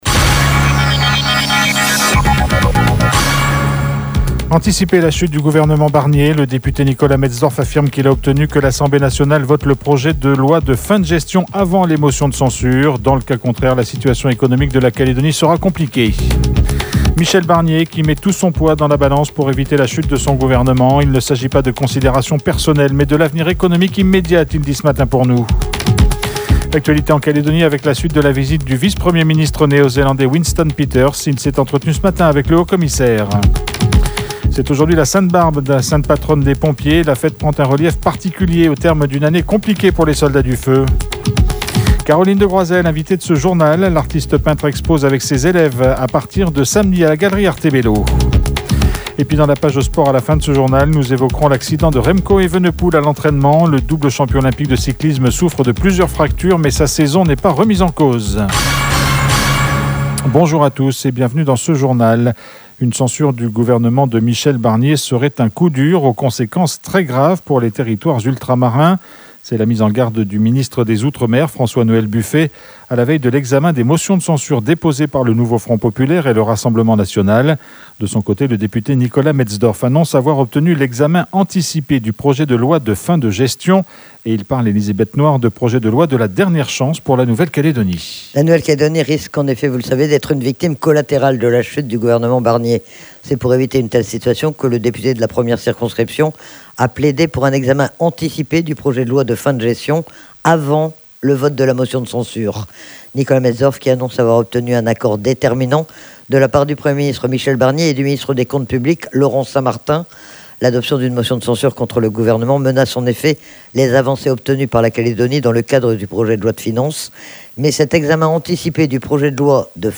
Le Journal